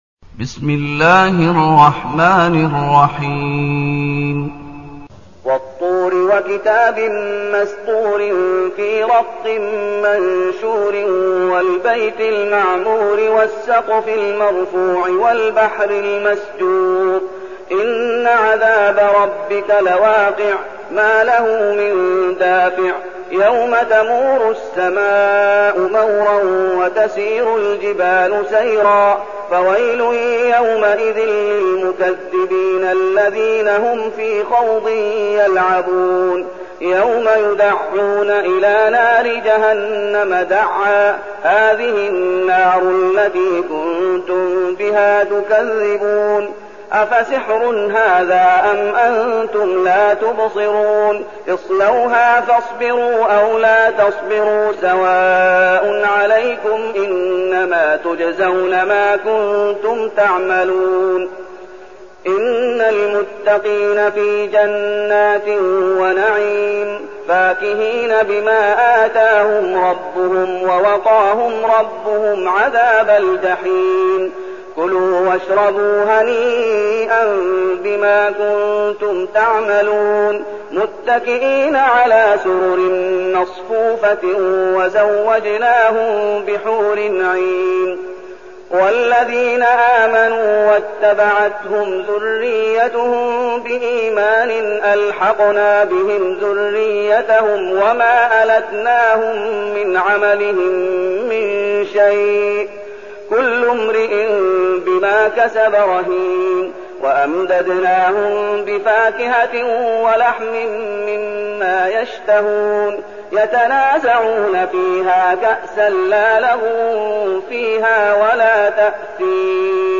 المكان: المسجد النبوي الشيخ: فضيلة الشيخ محمد أيوب فضيلة الشيخ محمد أيوب الطور